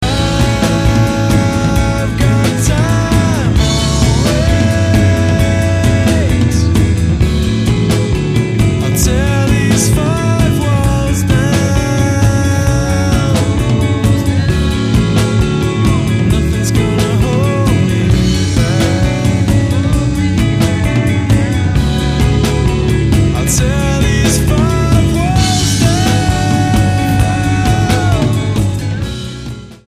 STYLE: Rock
Bristol-based four-piece